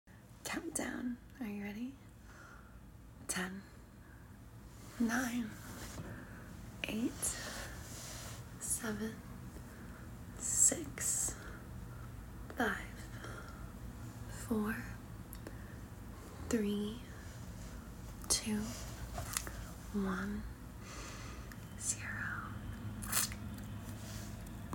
Countdown ! sound effects free download